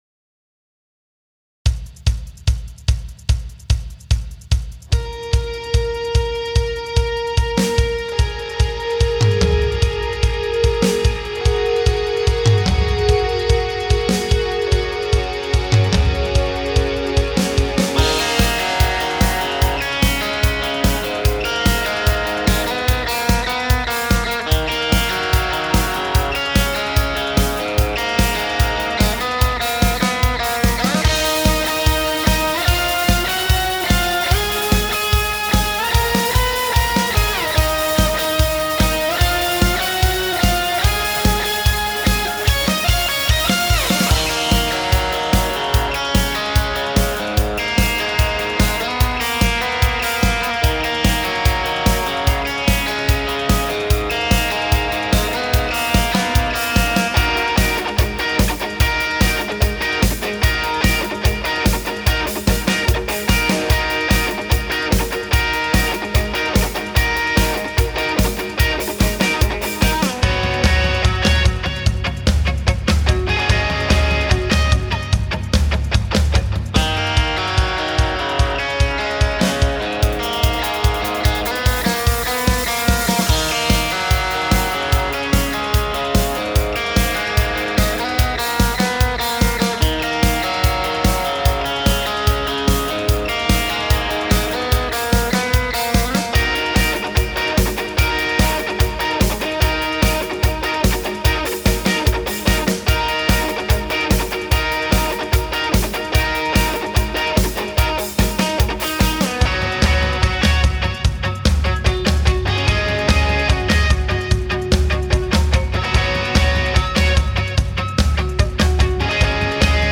BPM : 147
Tuning : Eb
Without vocals
Based on the BBC live version